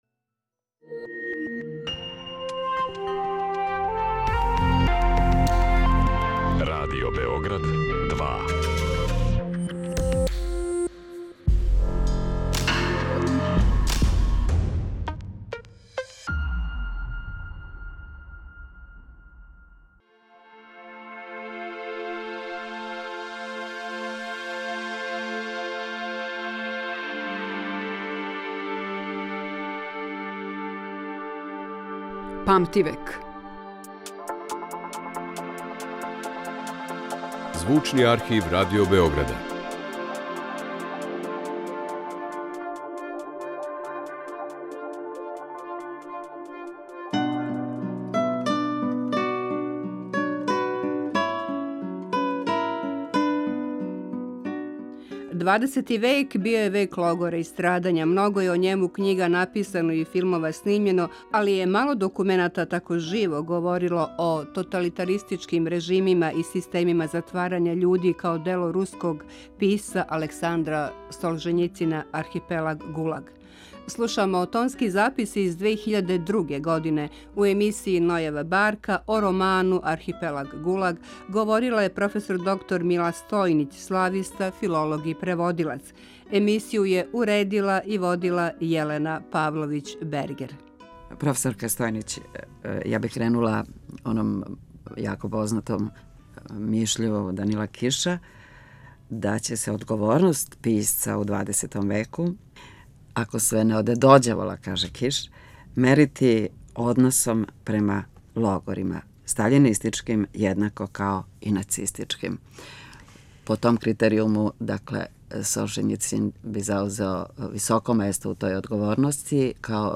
Слушамо тонски запис из 2002. године.